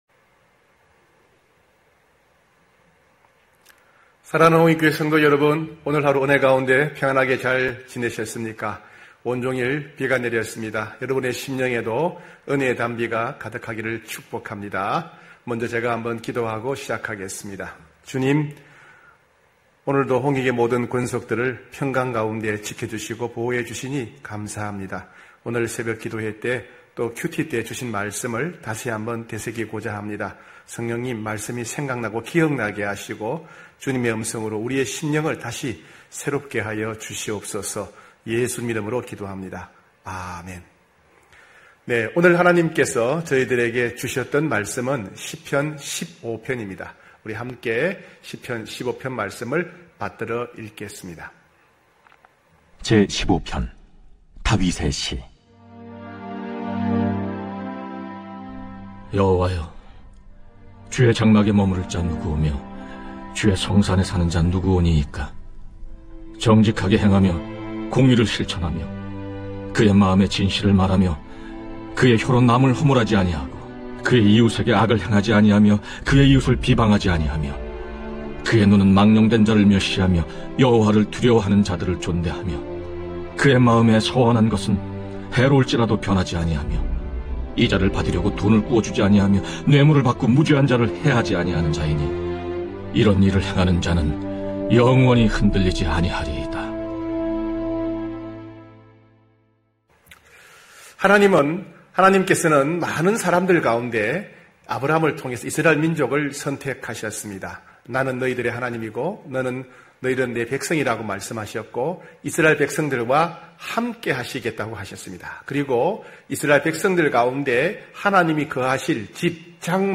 9시홍익가족예배(5월15일).mp3